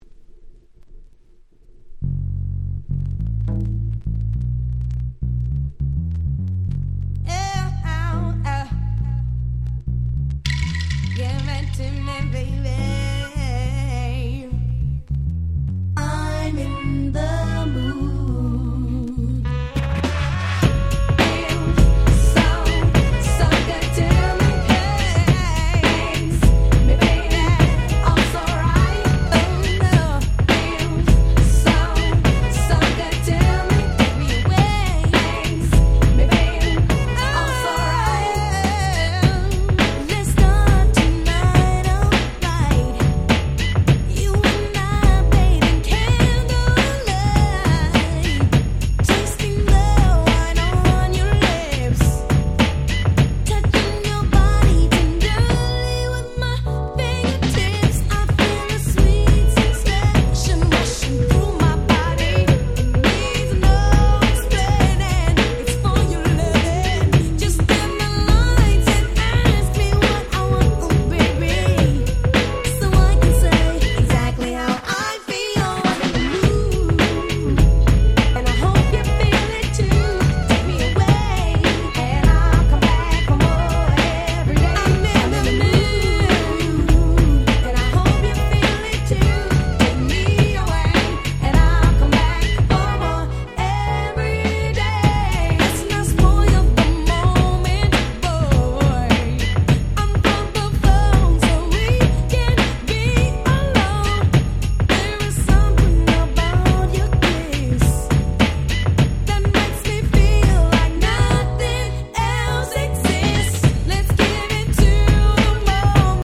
【Media】Vinyl 12'' Single
90's R&B Classics !!